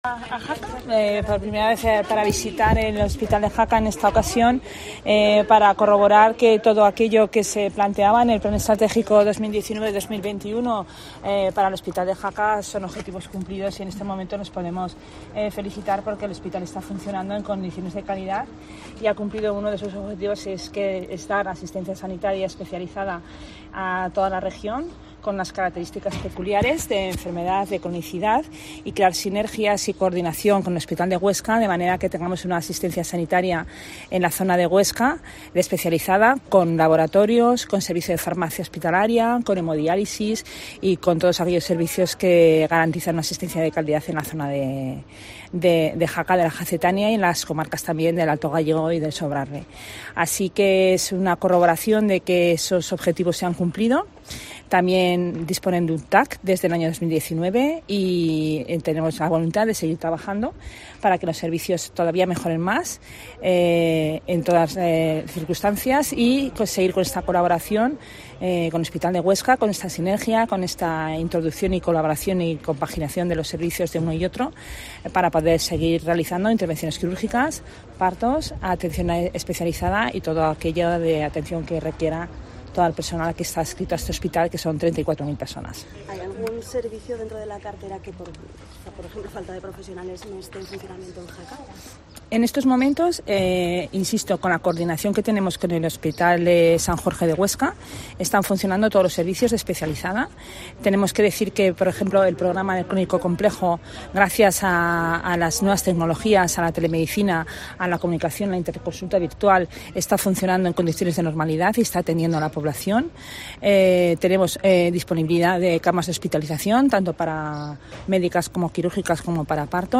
Rueda de prensa de Repollés en Jaca